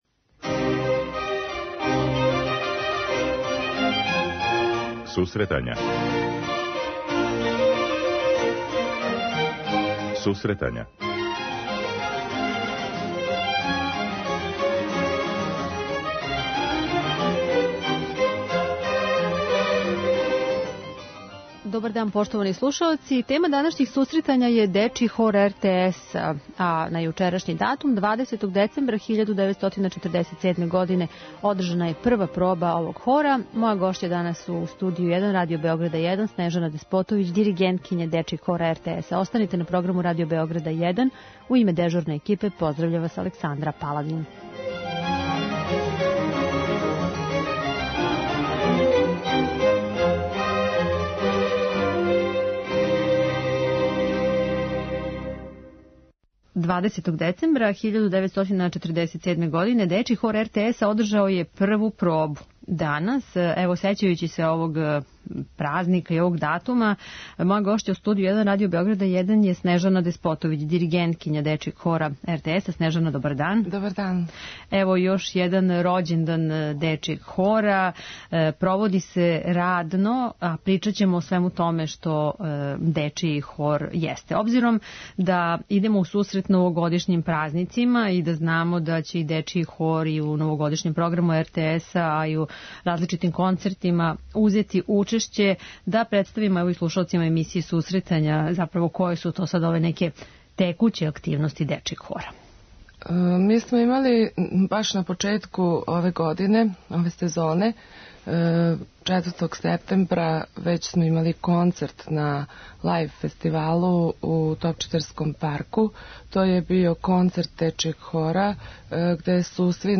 преузми : 9.80 MB Сусретања Autor: Музичка редакција Емисија за оне који воле уметничку музику.